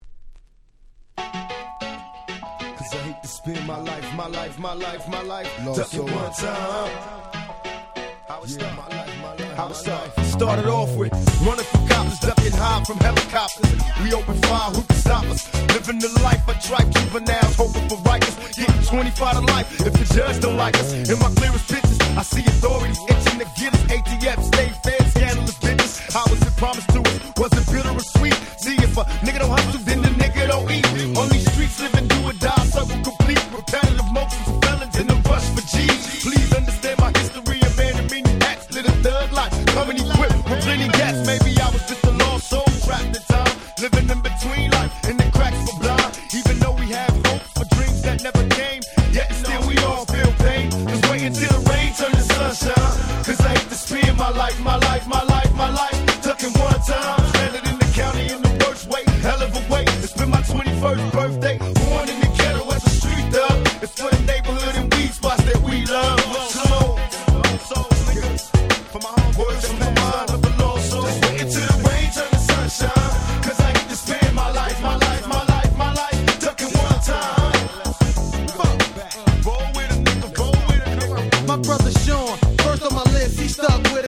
90's West Coast Hip Hop Classics !!
両面共に日本人受け抜群のメロディアスな楽曲でオススメ！！
G-Rap Gangsta Rap